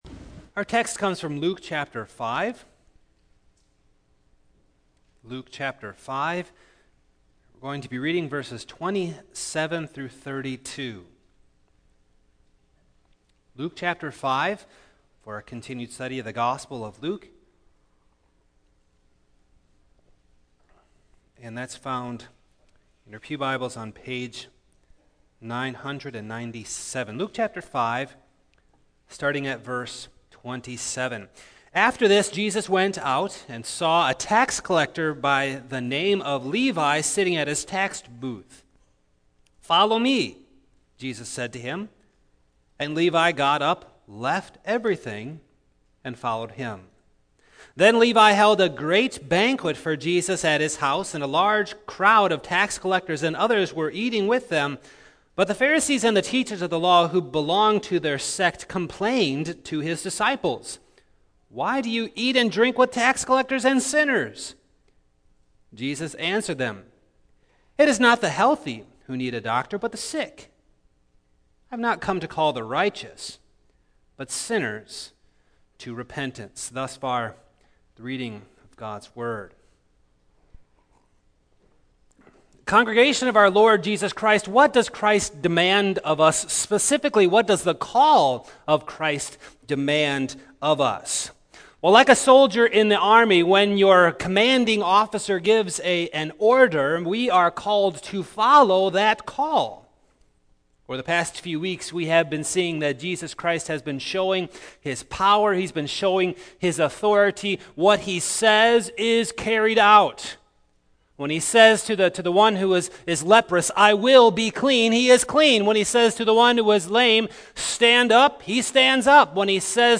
2016 The Radical Call to Follow Preacher